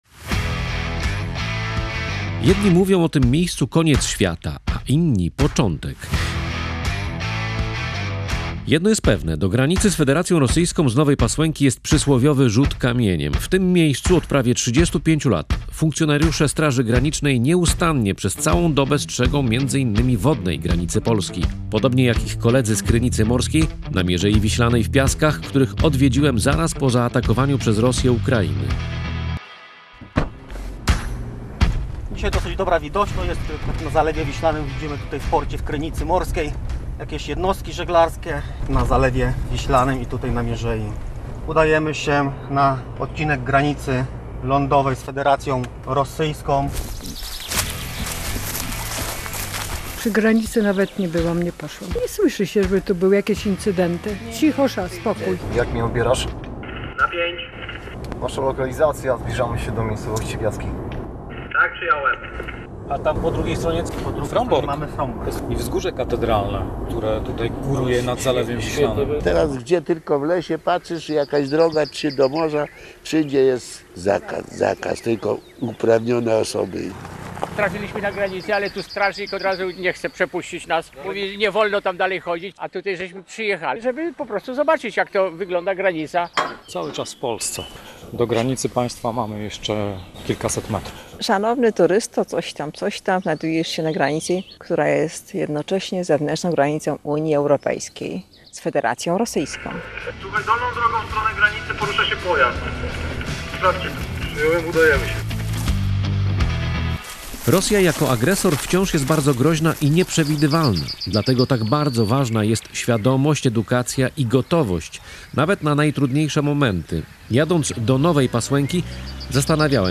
Czuwanie, odwaga, poświęcenie. Reportaż „Koniec świata” o służbie w Straży Granicznej
Jednostka Straży Granicznej w Nowej Pasłęce (fot. Radio Gdańsk)